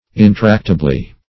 In*tract"a*bly, adv.